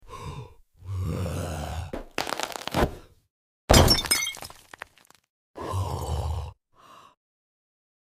The most satisfying slice you’ll sound effects free download
The most satisfying slice you’ll hear today!